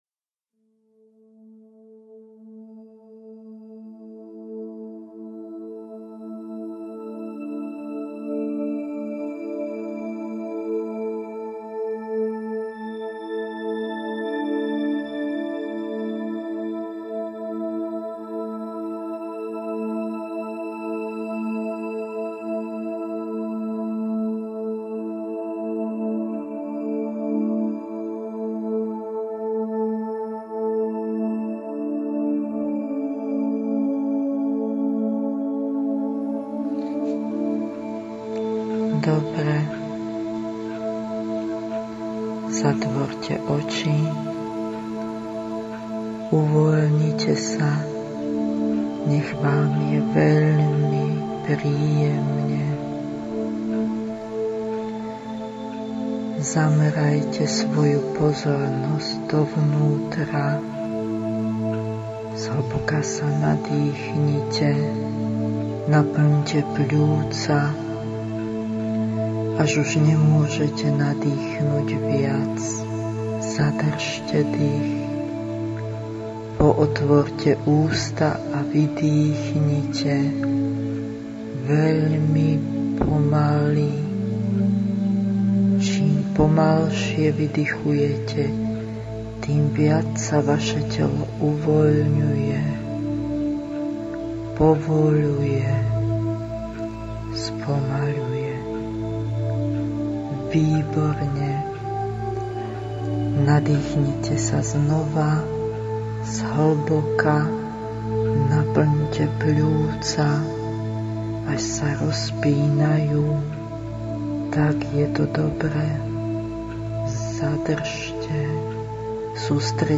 Meditácia na prechod do mimofyzickej reality – súbor
meditacia-zrkadlo-na-noc.mp3